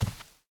cloth3.ogg